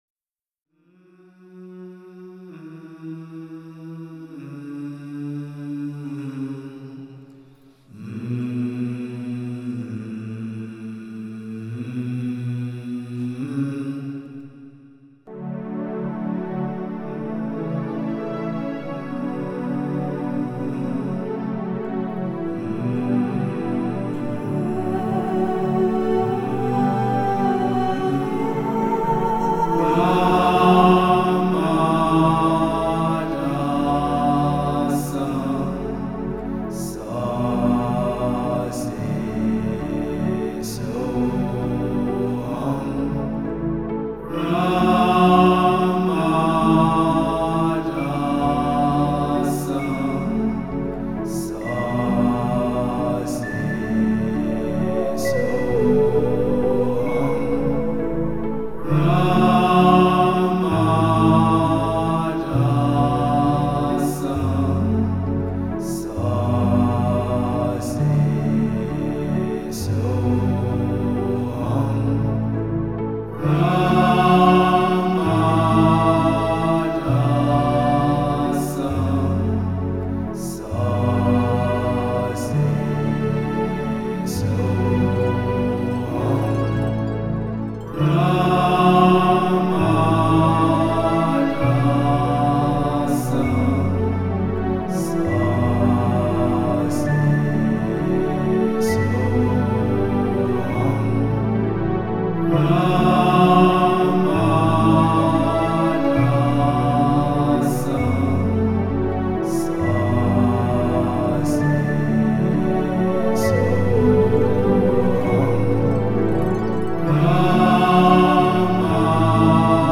Ein Mantra ist eine Tonfolge bestimmter Laute, deren Frequenz in bestimmten Bereichen unseres Körpers Resonanz findet.
Es ist ein Heilmantra und heißt Shushuma Mantra und ist viele Tausende Jahre alt.
01-ra-ma-da-sa-slow-version.m4a